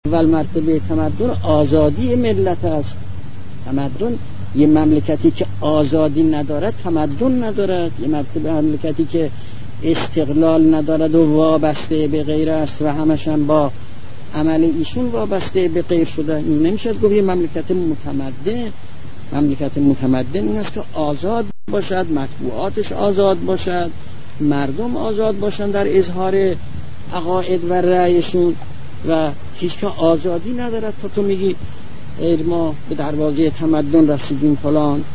Аятолла Хомейни из раннего выступления